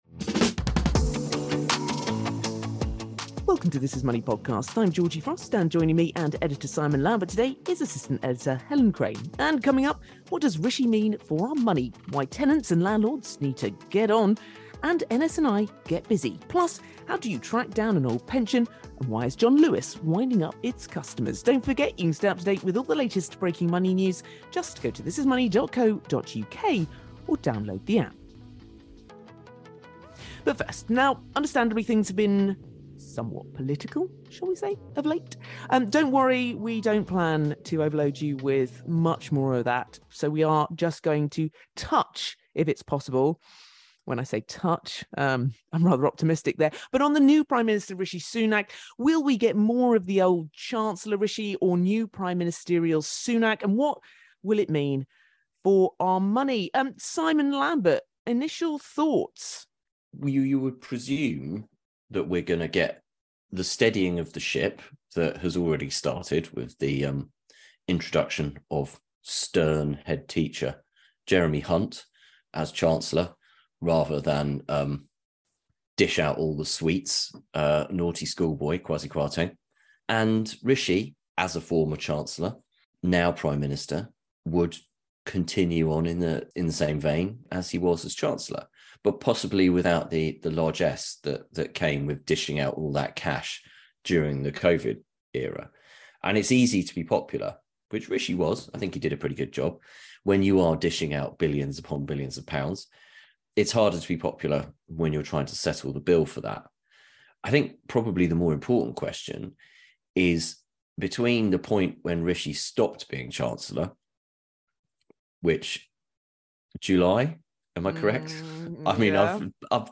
Business News, Business, Investing, News